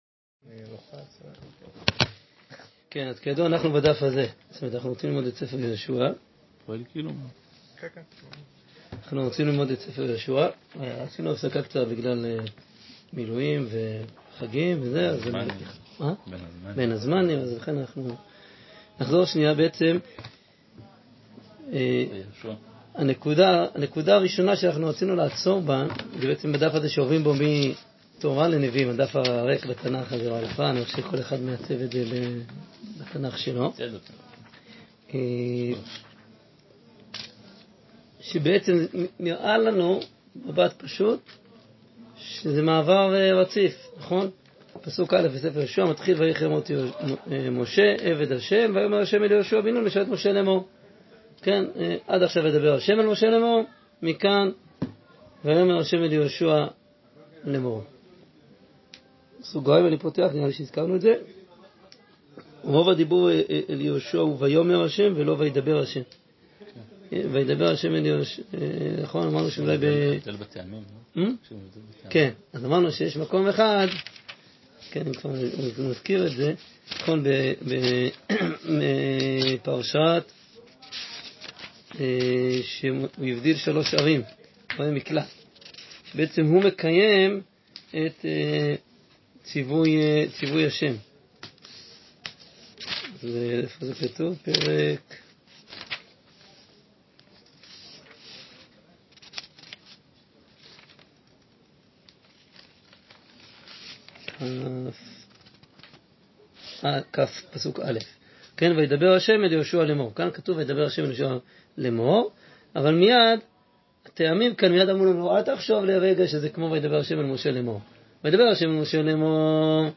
ספר יהושע שיעור 3